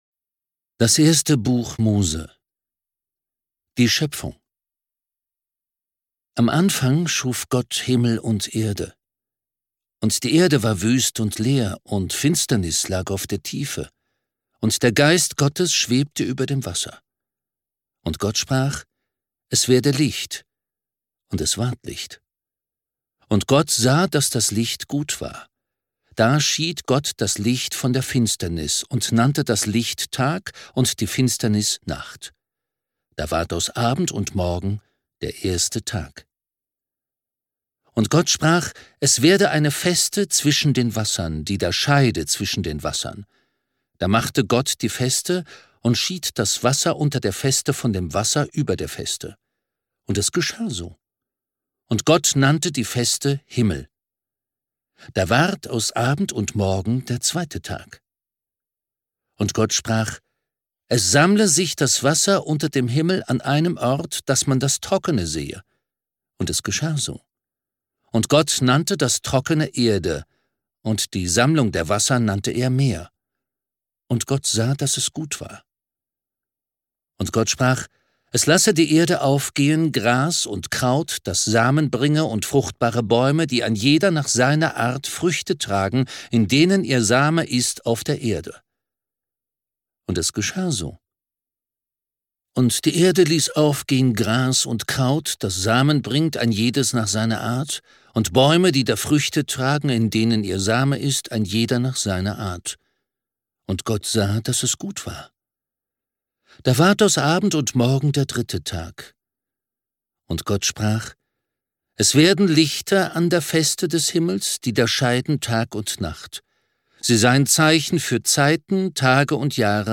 Ungekürzte Lesung des Alten und Neuen Testaments und der Apokryphen in der Lutherübersetzung 2017 (86 CDs)
Rufus Beck (Sprecher)
Ungekürzte Lesung mit Rufus Beck